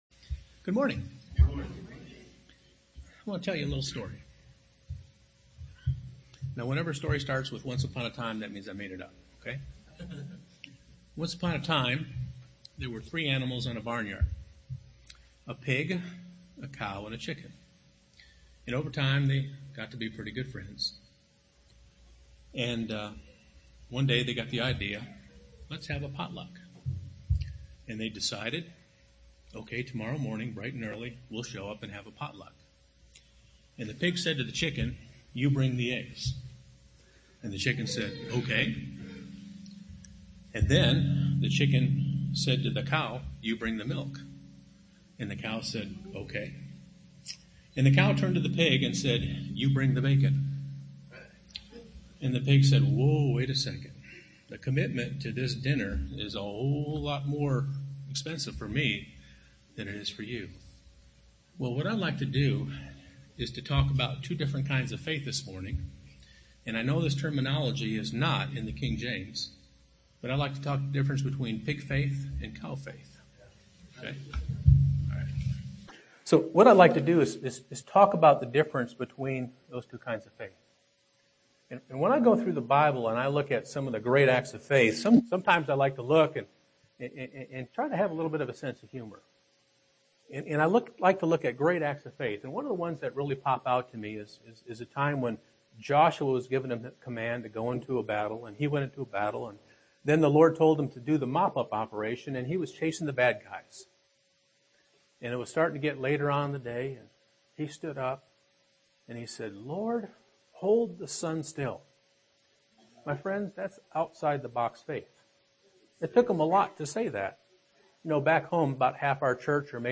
Sermons preached at Bethlehem – OKC • Page 78